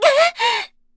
Mediana-Damage_kr_01.wav